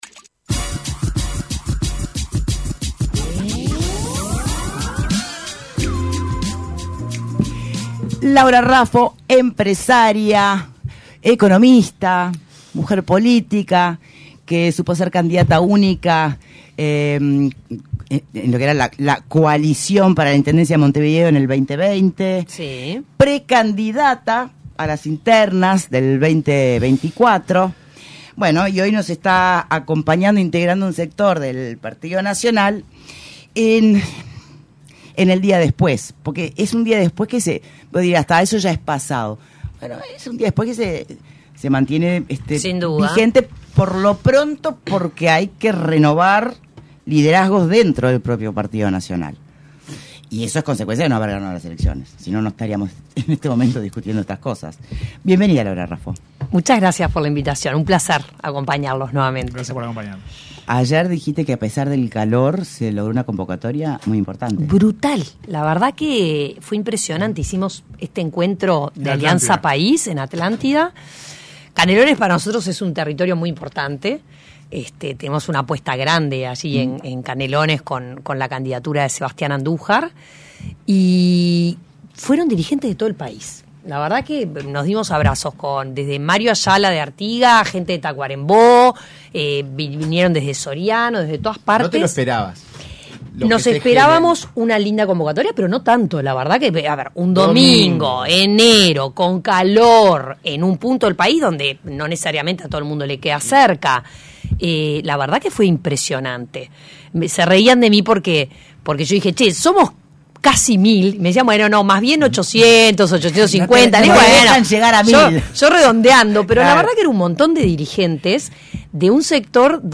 Entrevista a Laura Raffo